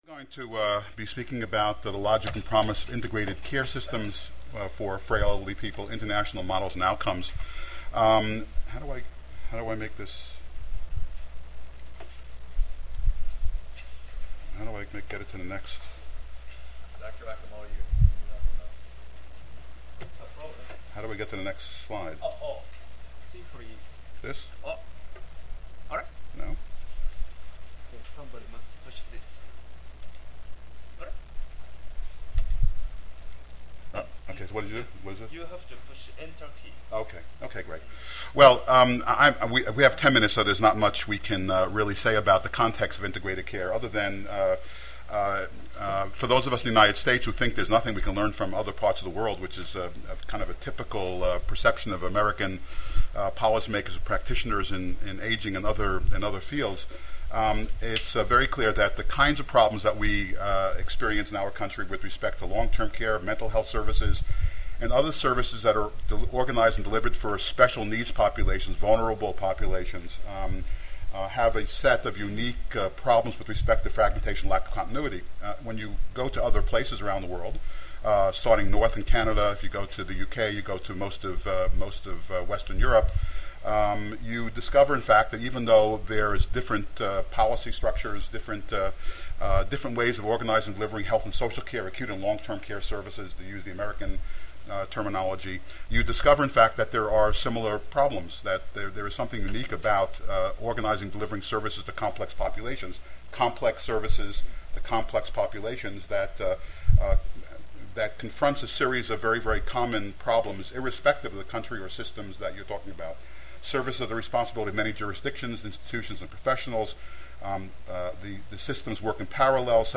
Audience participation will be sought.